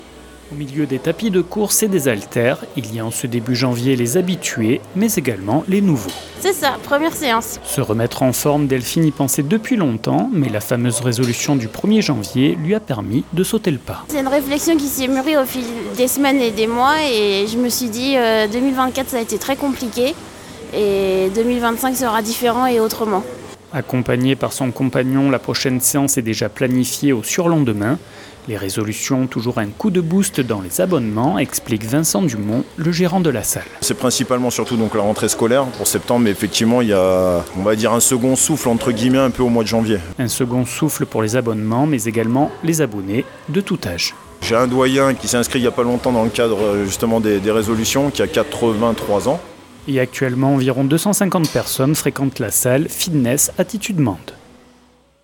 Direction la salle de sport Fitness Attitude Mende pour rencontrer les Lozériens et Lozériennes motivés à tenir le défi.
Reportage